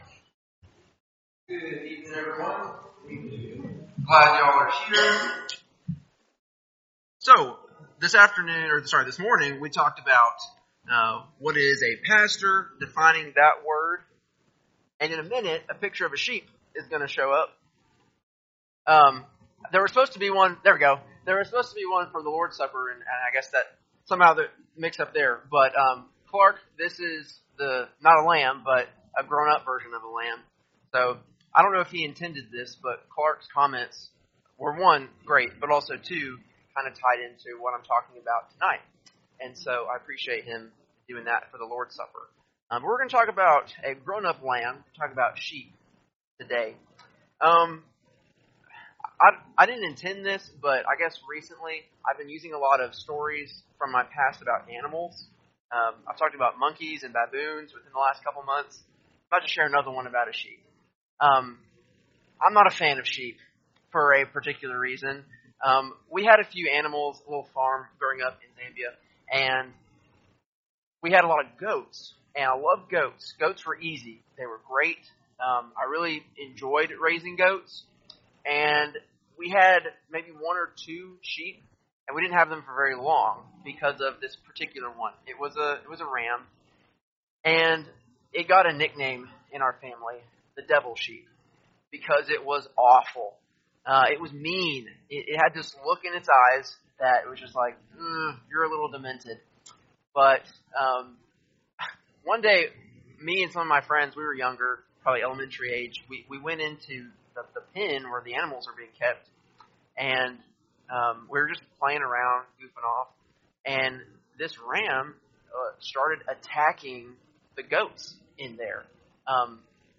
3-16-25-Sunday-PM-Sermon.mp3